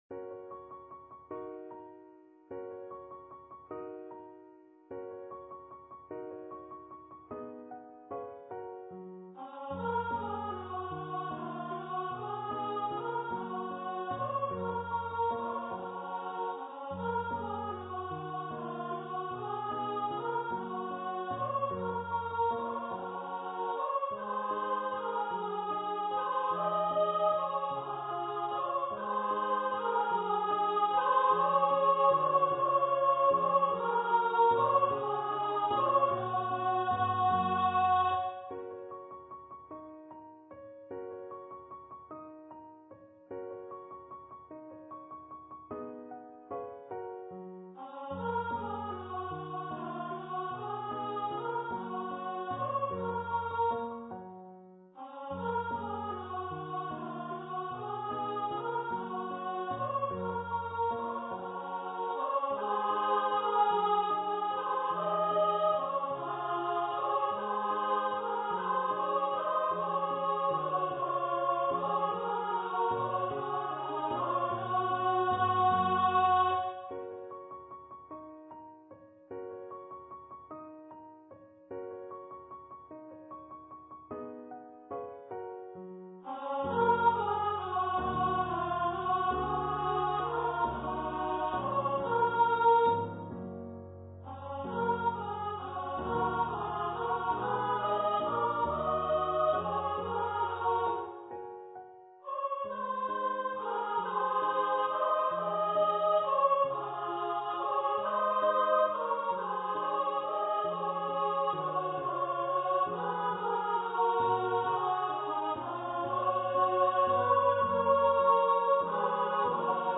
for female voice choir